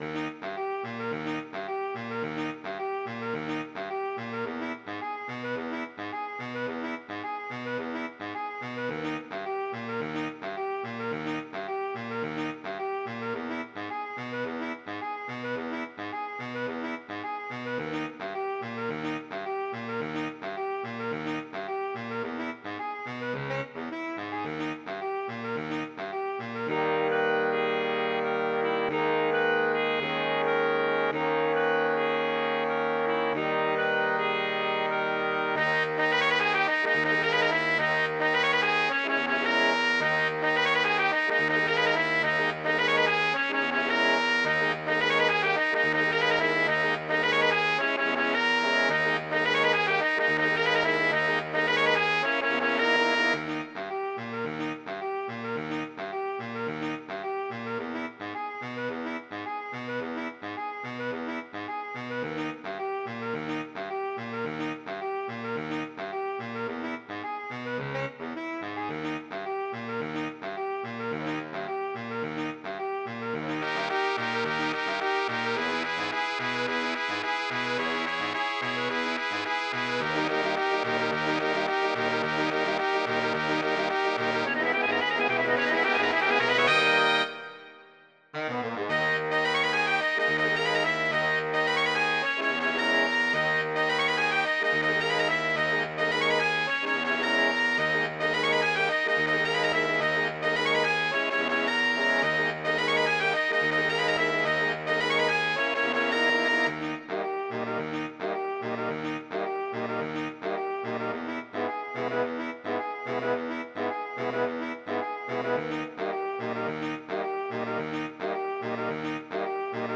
mp3 from midi